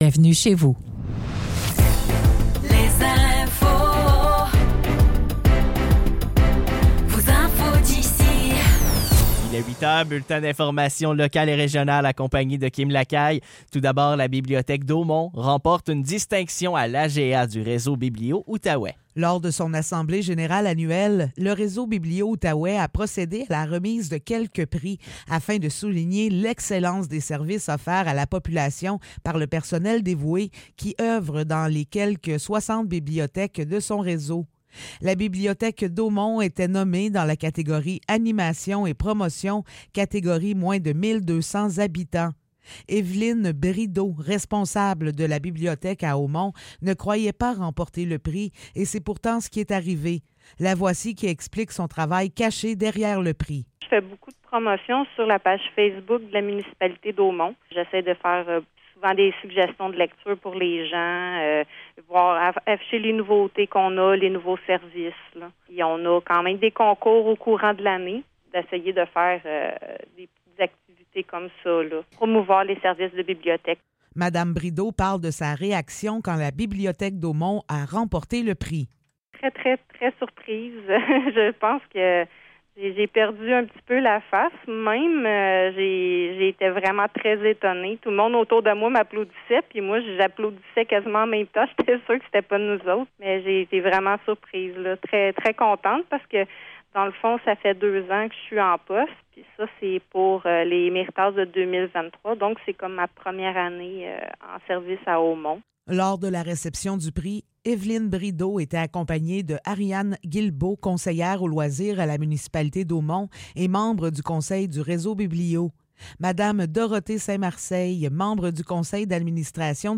Nouvelles locales - 27 juin 2024 - 8 h